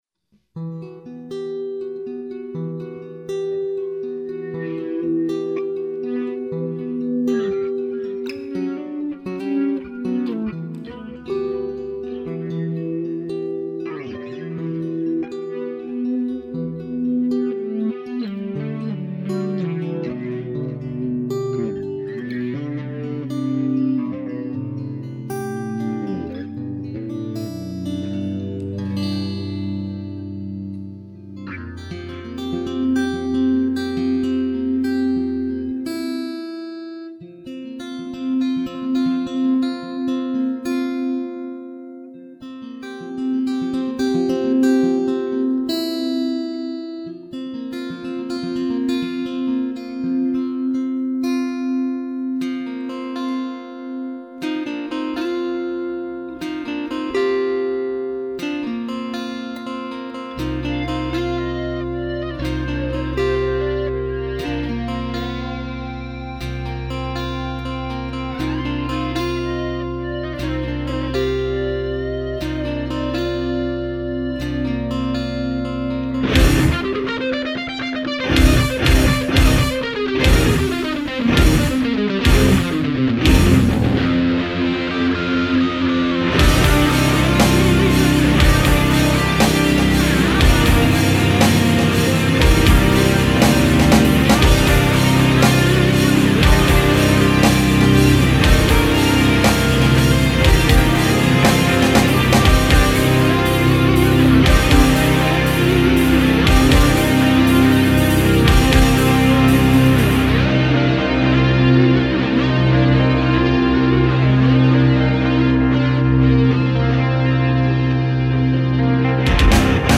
rockbanda